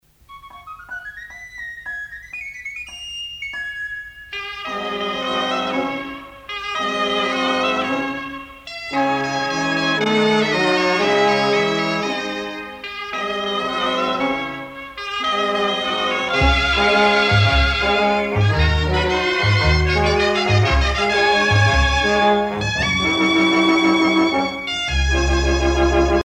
danse : sardane
Pièce musicale éditée